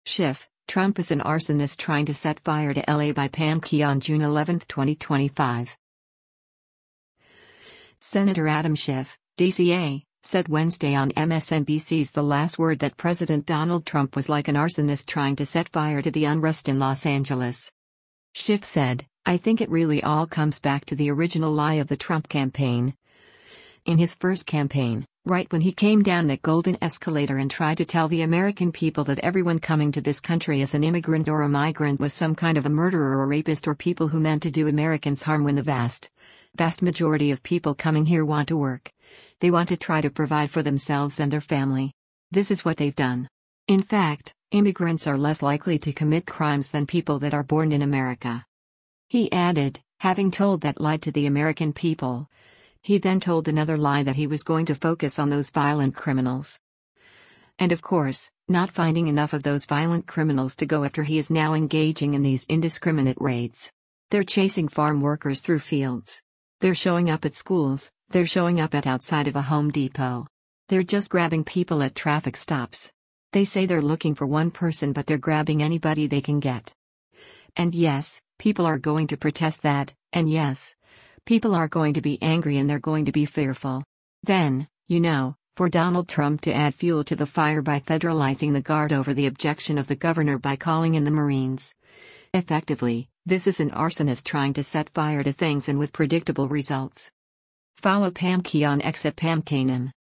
Senator Adam Schiff (D-CA) said Wednesday on MSNBC’s “The Last Word” that President Donald Trump was like an “arsonist trying to set fire” to the unrest in Los Angeles.